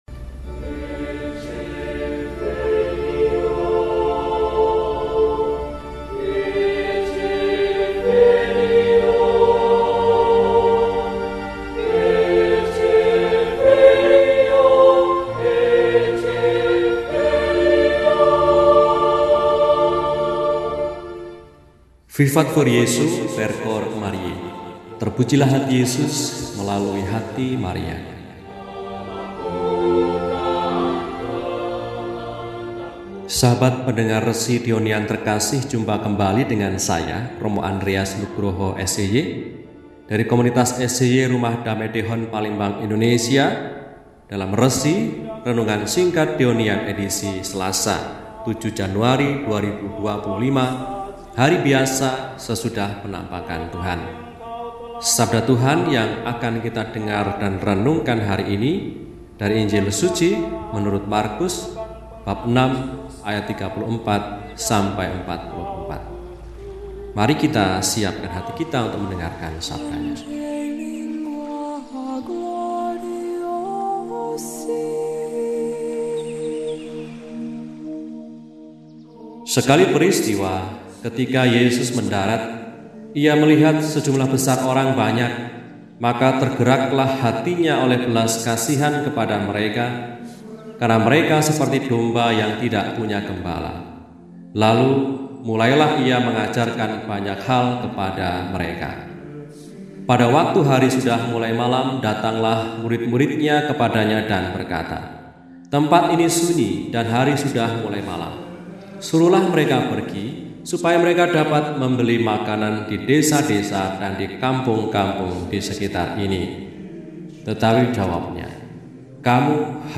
Selasa, 07 Januari 2024 – Hari Biasa Sesudah Penampakan Tuhan – RESI (Renungan Singkat) DEHONIAN